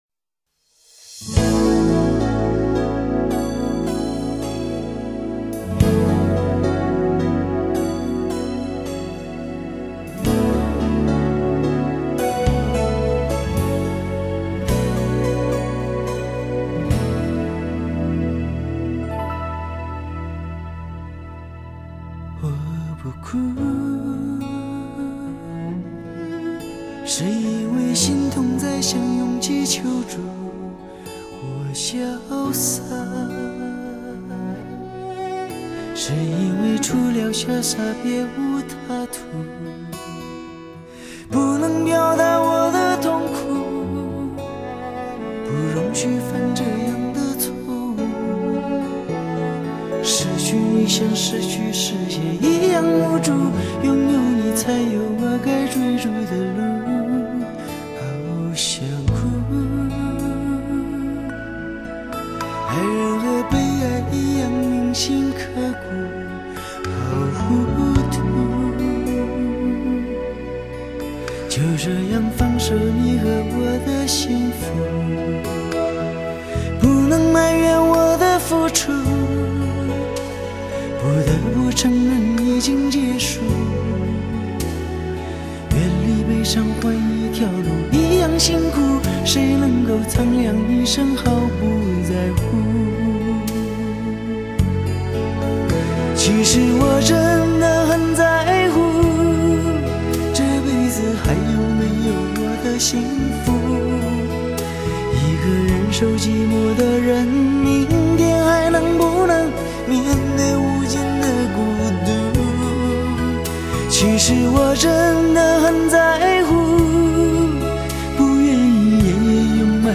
尤其令人折服的是他演唱中「憂鬱」氣息的運用，圓潤自如，流暢自然幾乎達到爐火純青的地步。